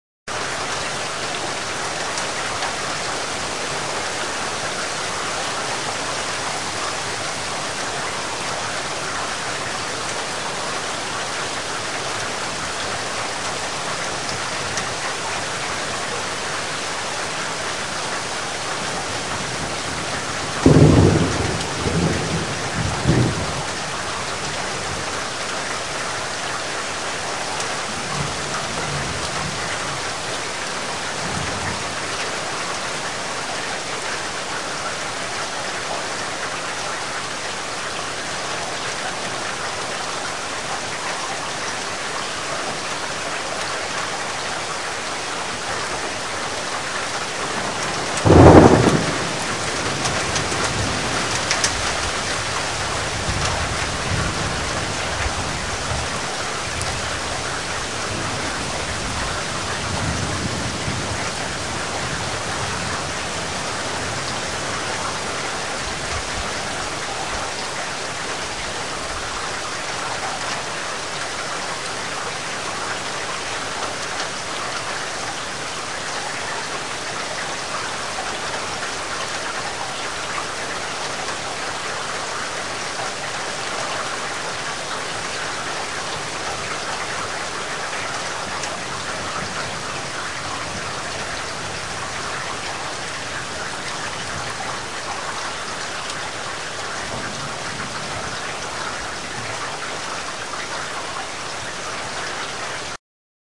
雨水 " 大雨滂沱，雷声阵阵
描述：届大雨和雷电的城市
标签： 大雨 暴雨 打雷
声道立体声